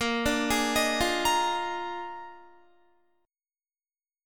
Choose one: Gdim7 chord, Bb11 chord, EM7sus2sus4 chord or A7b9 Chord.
Bb11 chord